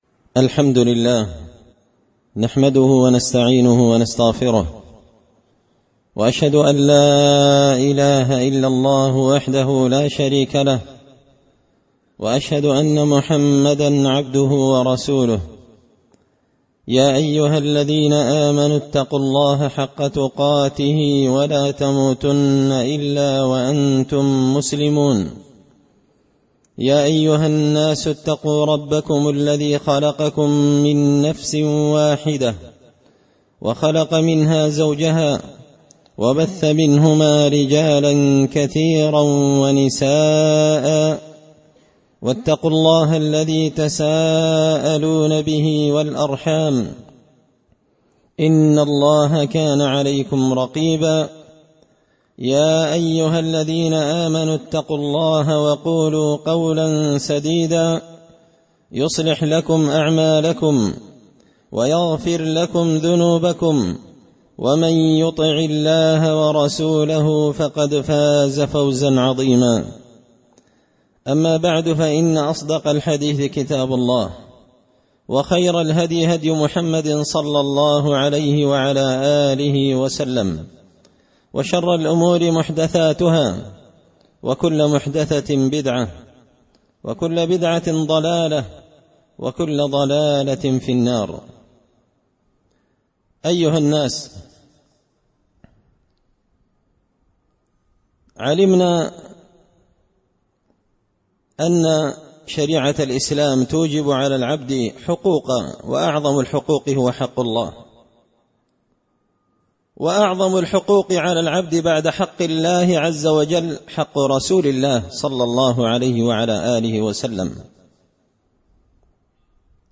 خطبة جمعة بعنوان – حق الرسول صلى الله عليه وسلم الجزء الأول
دار الحديث بمسجد الفرقان ـ قشن ـ المهرة ـ اليمن